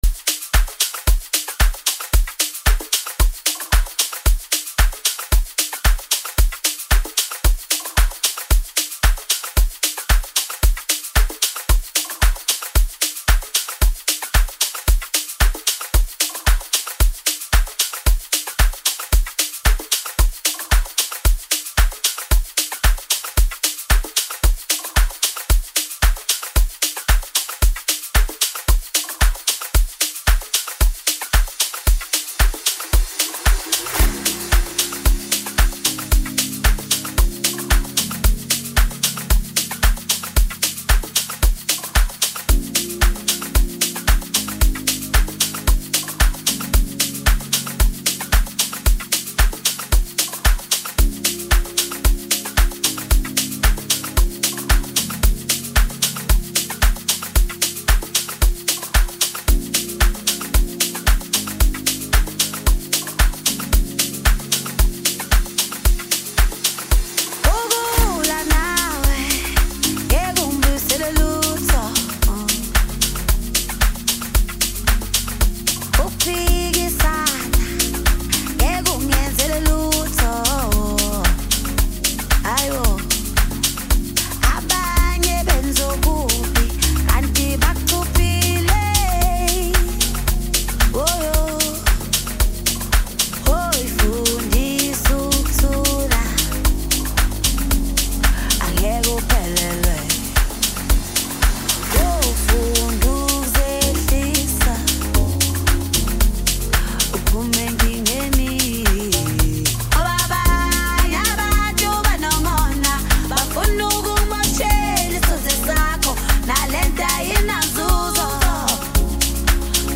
Home » Amapiano » DJ Mix » Hip Hop
South African singer